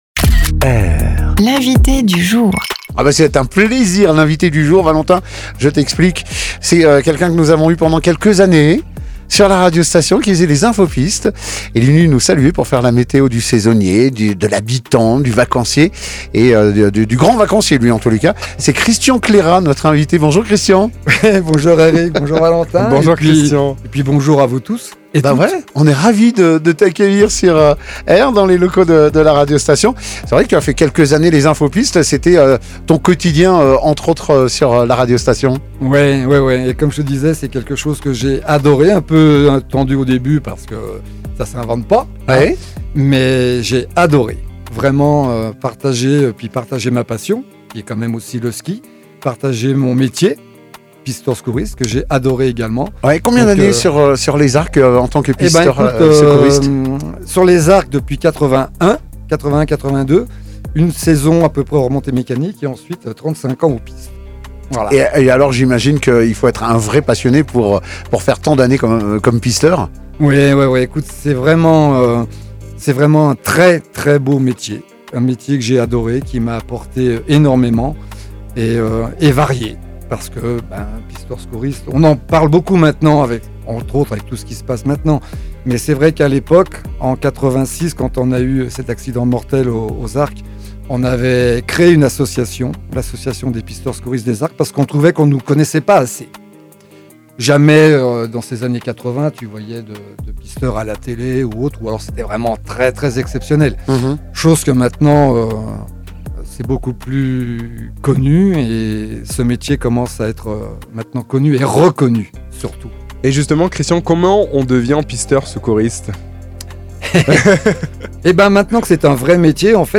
ITW Previous post L’Info Vallée du 19 Janvier 2026 Next post Tignes Info